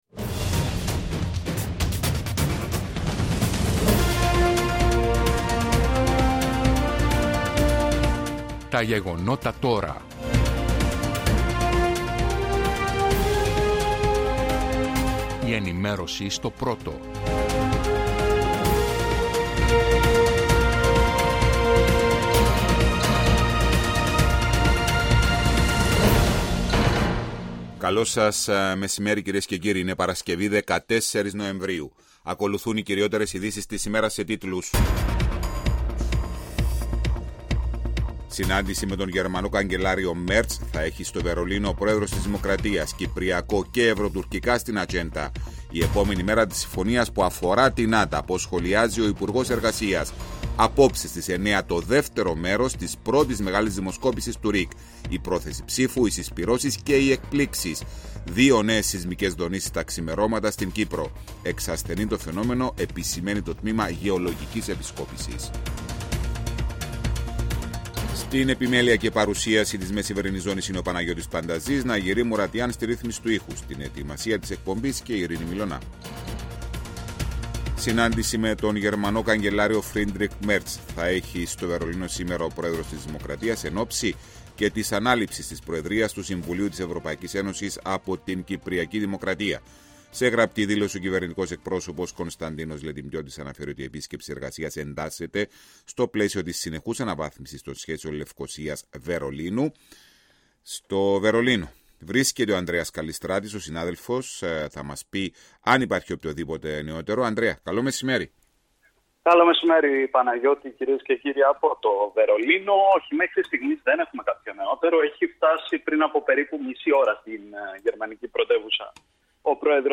Η επικαιρότητα της ημέρας αναλυτικά με ρεπορτάζ, συνεντεύξεις και ανταποκρίσεις από Κύπρο και εξωτερικό.